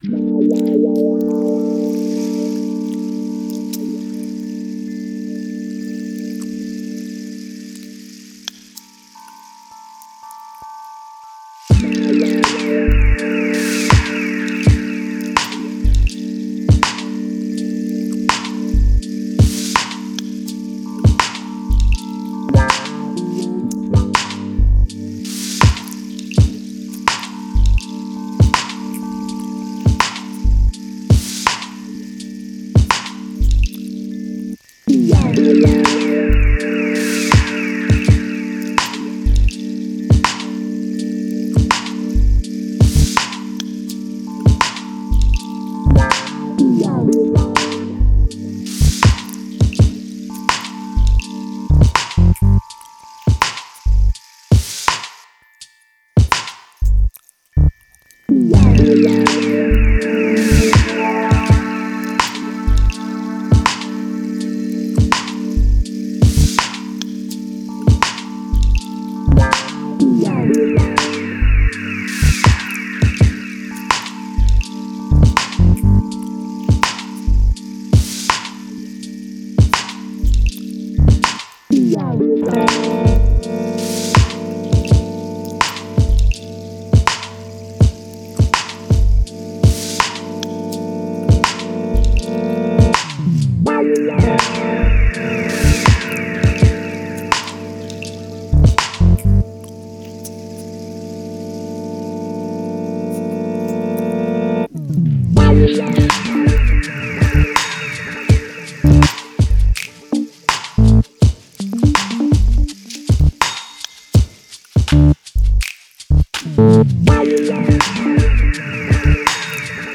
Slow warm soulful groove with dark night atmosphere.